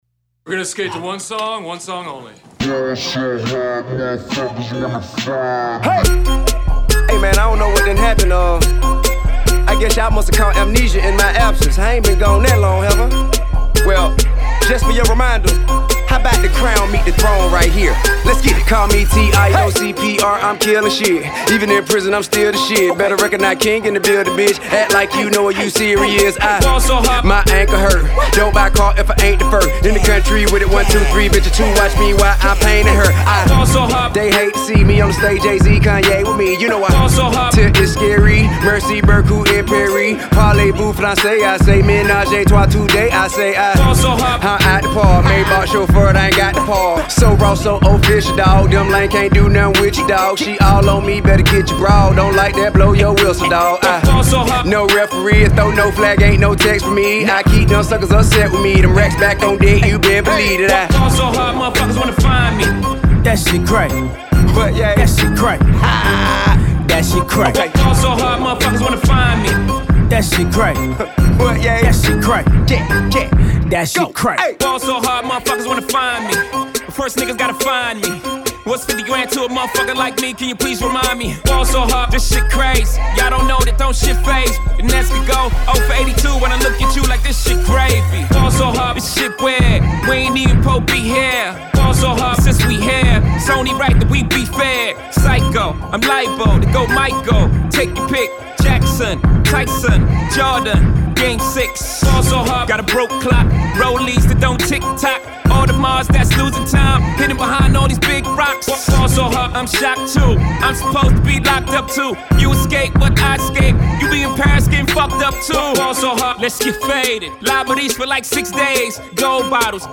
southern bounce